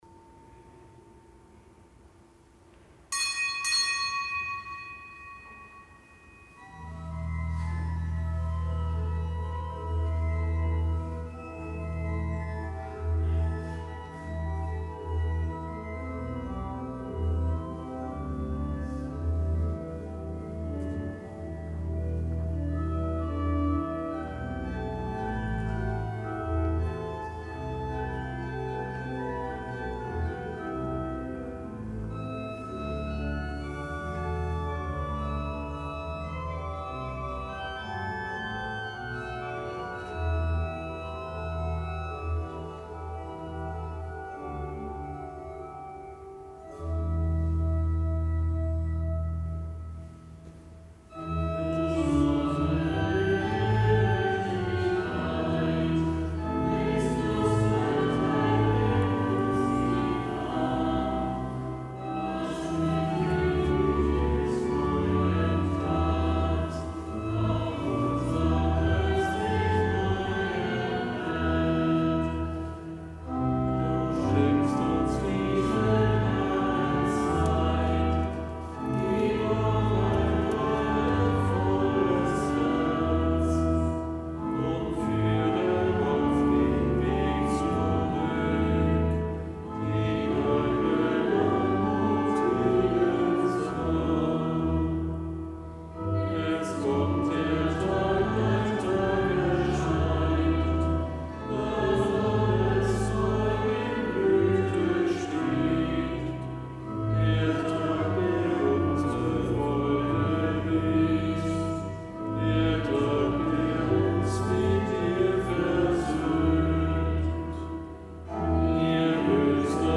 Kapitelsmesse am Samstag der vierten Fastenwoche
Kapitelsmesse aus dem Kölner Dom am Samstag der vierten Fastenwoche